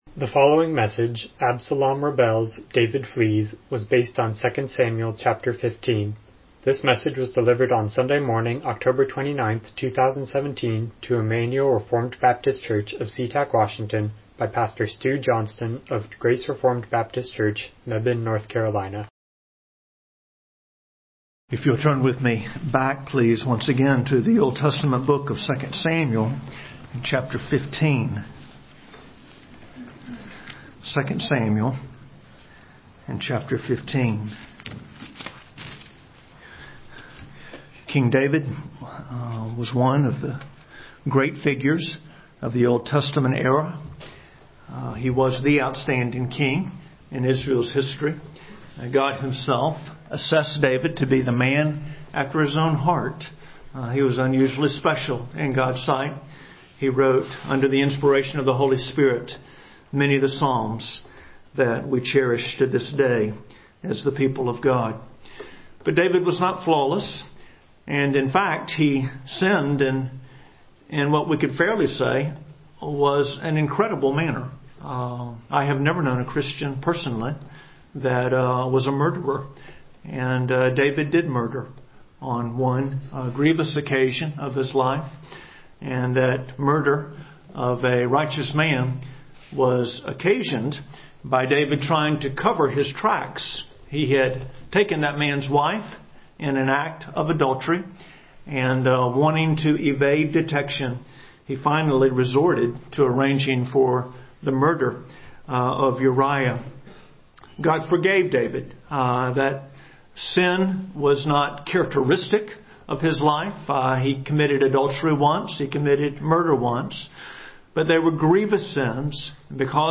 Miscellaneous Passage: 2 Samuel 15:1-37 Service Type: Morning Worship « God Unchanging Absalom’s Defeat